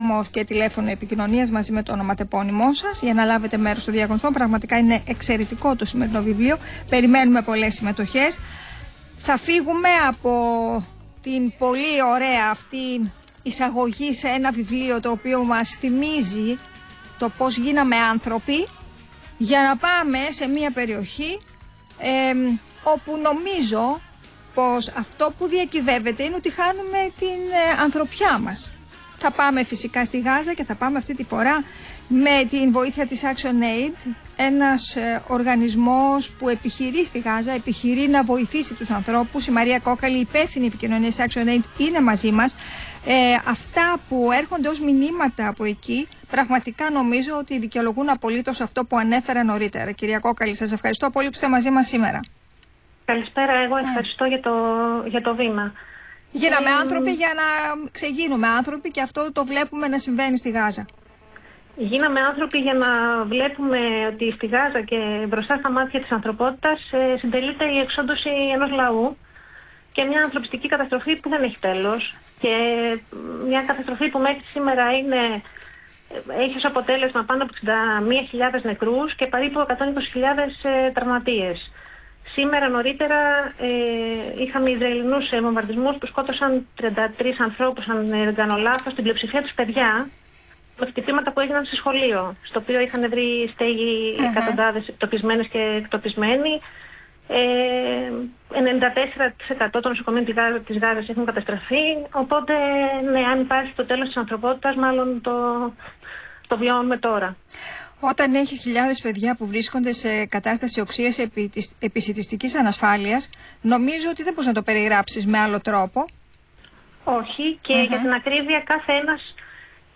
Συνέντευξη στον Αθήνα 9,84: Ανθρωπιστική κρίση στη Γάζα & πώς μπορούμε να βοηθήσουμε